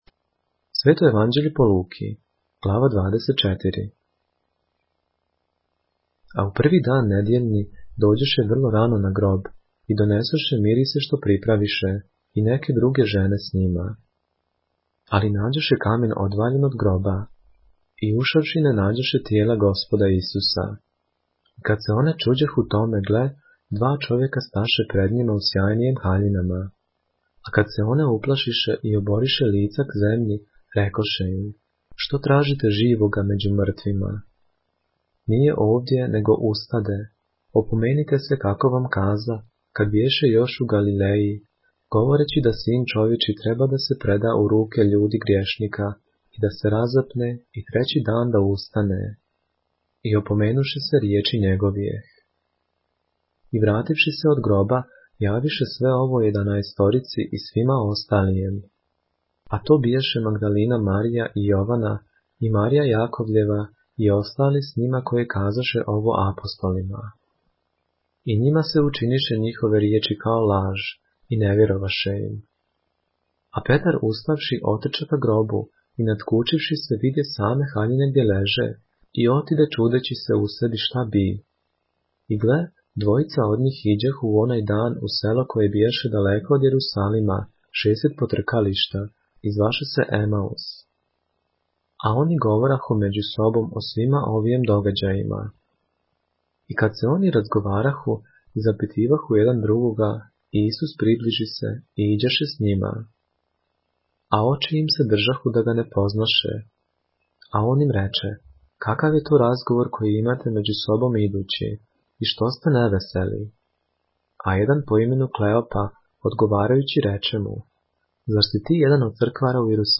поглавље српске Библије - са аудио нарације - Luke, chapter 24 of the Holy Bible in the Serbian language